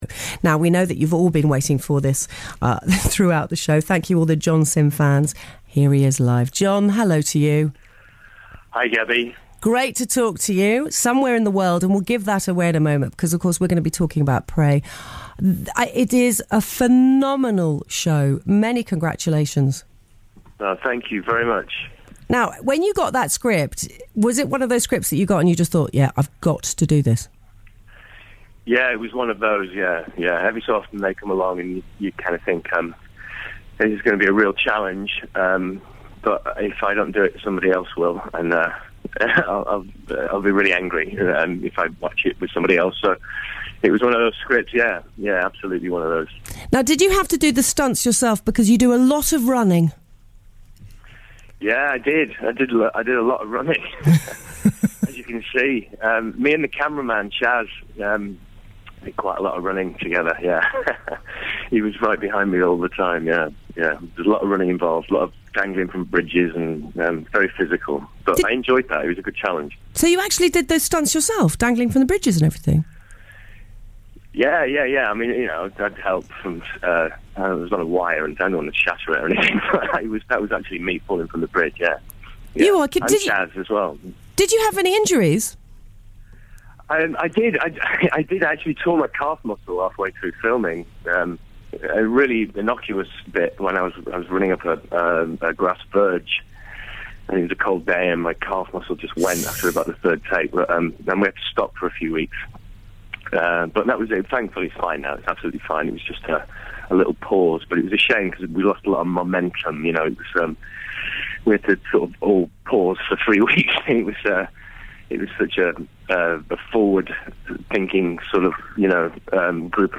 Radio Interview: Gaby Roslin with John Simm